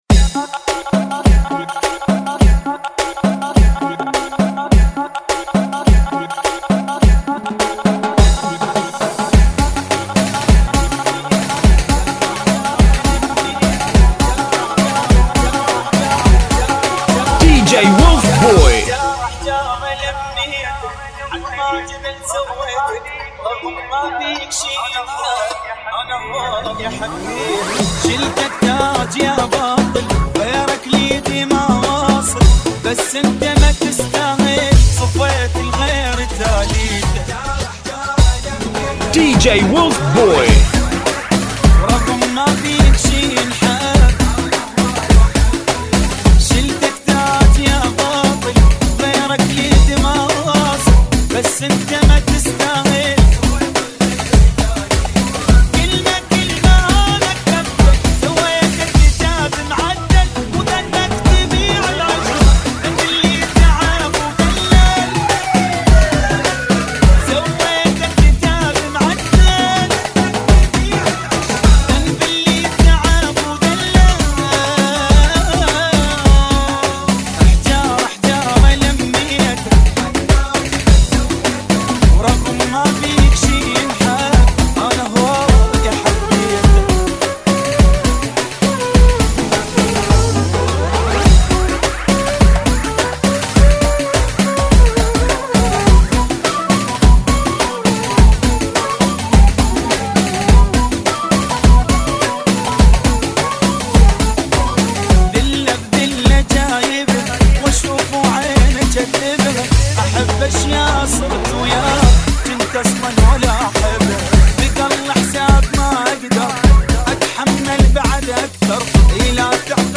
[ 104 Bpm ]